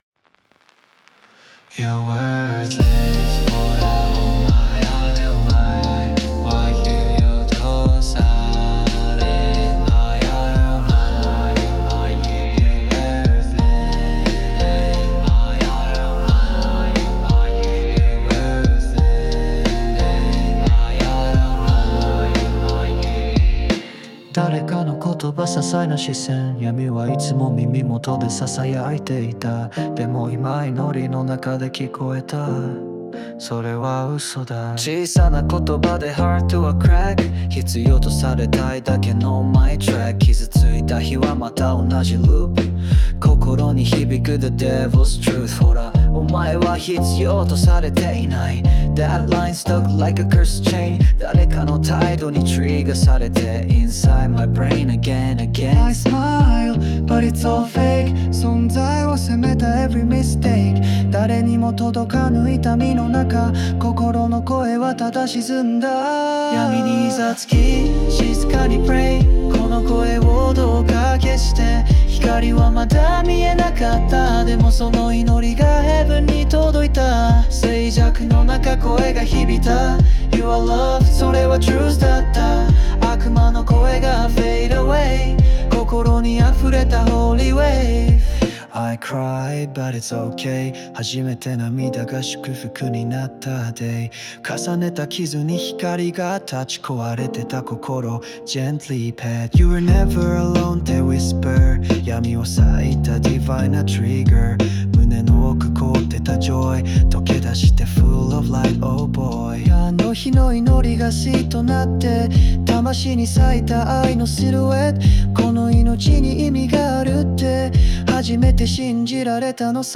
静かに祈るような気持ちで、この曲を聴いてみてください。